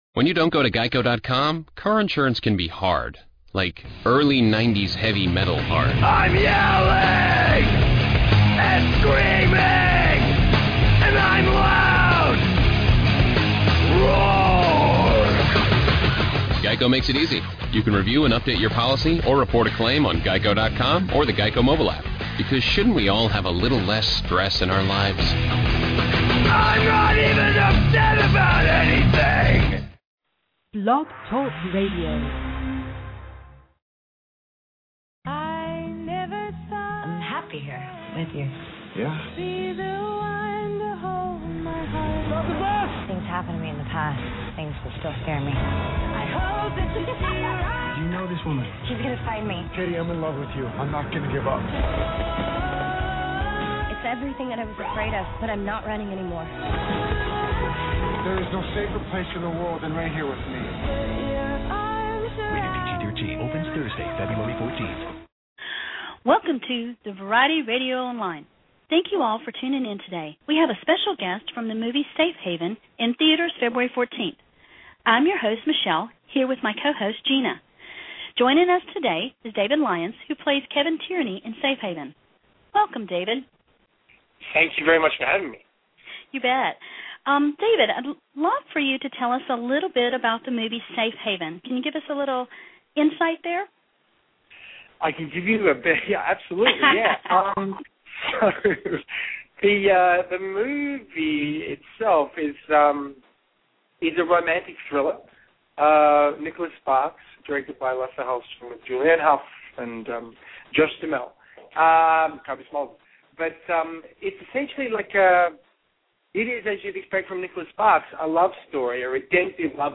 David Lyons – Interview